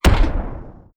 EXPLOSION_Arcade_19_mono.wav